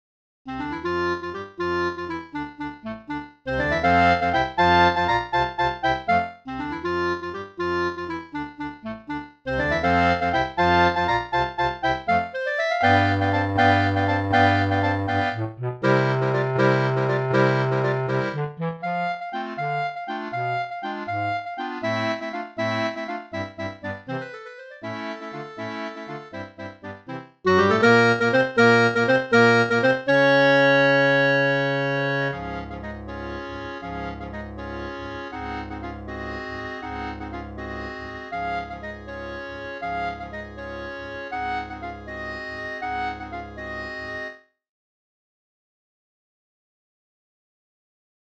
FOR CLARINET CHOIR